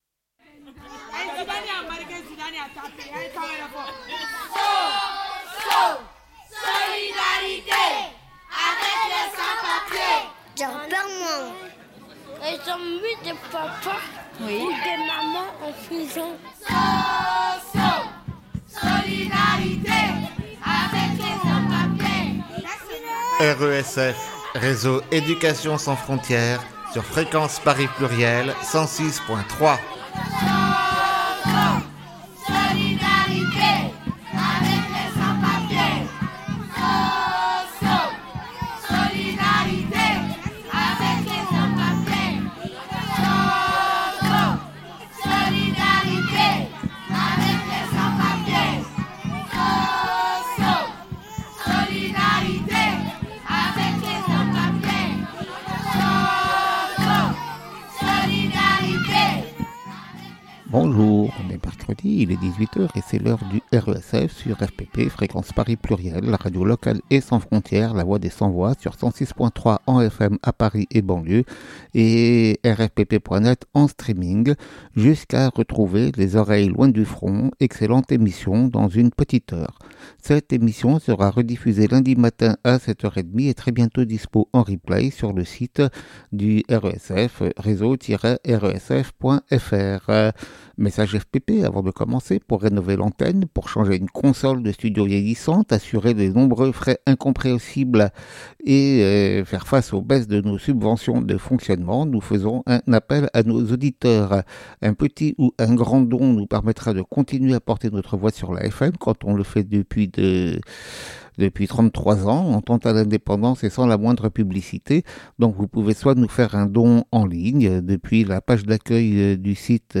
Prises de paroles des familles de victimes - suite du reportage diffusé le 18 avril.